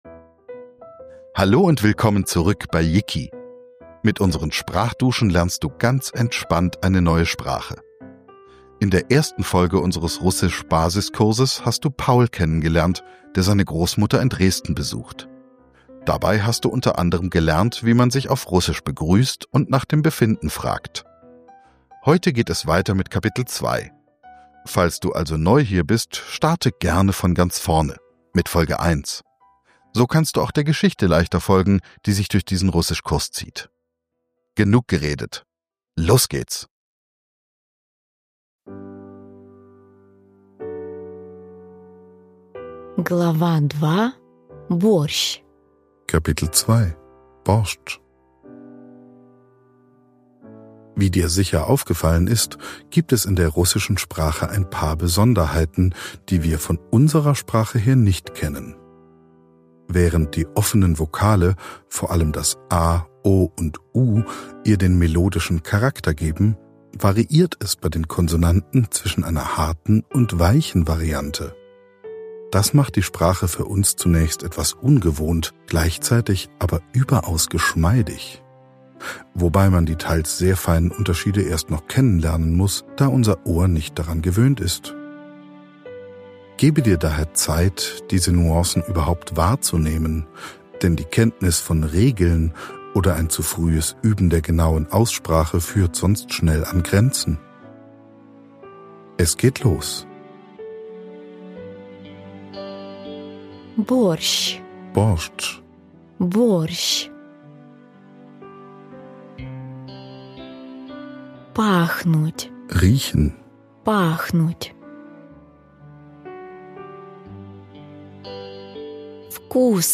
Beschreibung vor 4 Monaten In dieser Folge kocht Pauls Großmutter Borschtsch. Neben neuen Alltagsausdrücken lernst du, wie du auf Russisch über Essen und Getränke sprichst, Rezepte beschreibst und über das Alter redest. Dich erwarten viele neue Vokabeln, Dialoge und Sätze zum Nachsprechen, um gleichzeitig Russisch sprechen zu lernen.